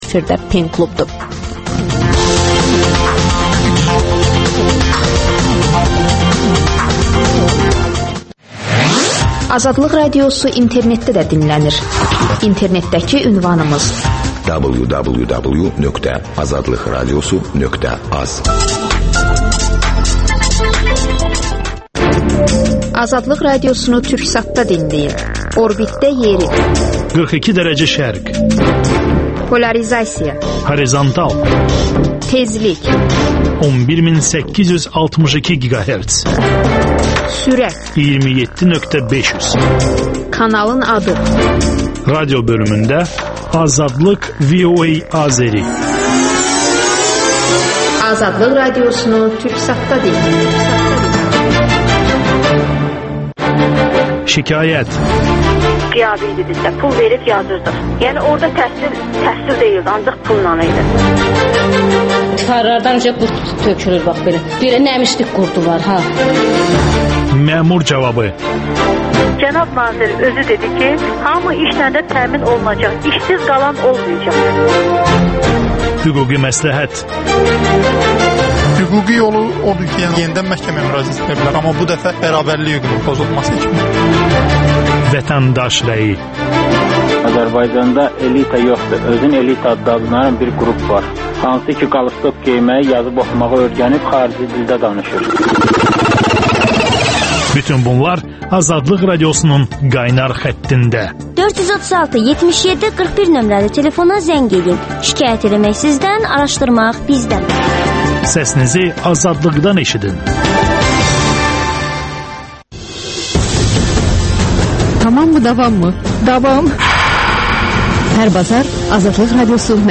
Azadlıq Radiosunun «Pen klub» verilişindəki bu müzakirədə türk yazarı